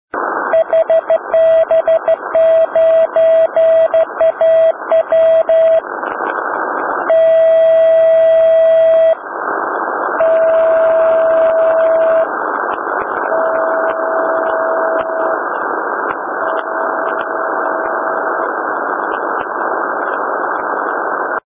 Bake-SenderThe transmission commences with the call sign HB9AW in CW (100HA1B), followed by five 2 seconds-long dashes. The dashes are each accurately attenuated in the EIRP power sequence 10W / 5 W / 1Watt / 100mW concluding with 10mW and currently repeats every 5 minutes, commencing on the hour.